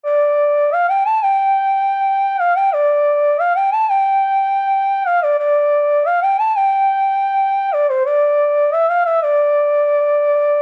嘻哈长笛
Tag: 90 bpm Hip Hop Loops Flute Loops 1.79 MB wav Key : Unknown